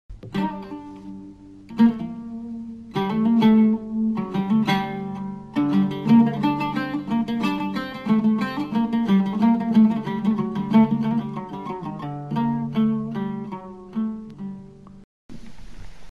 (Taqseem Oud speakicon).After the Bashraf and a group of Muwashahat, a musical group would play a musical piece shorter than the Bashraf.